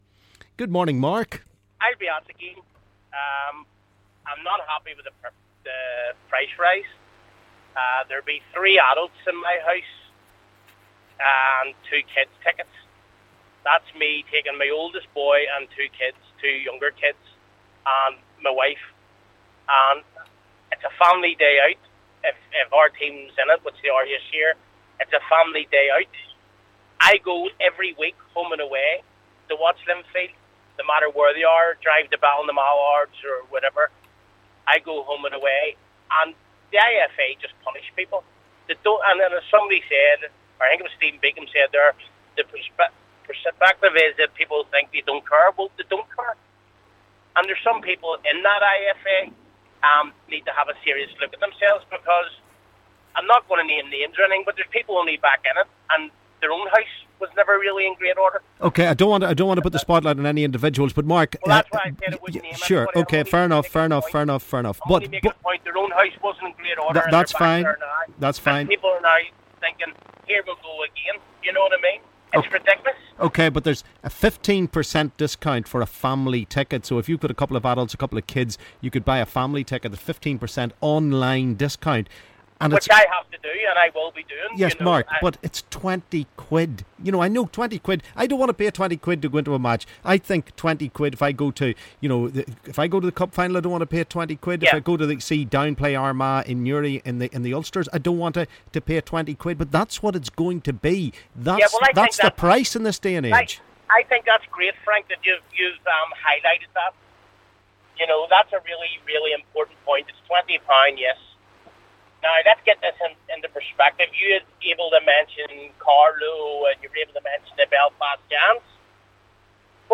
LISTEN: Callers are complaining about the price of Irish Cup Final tickets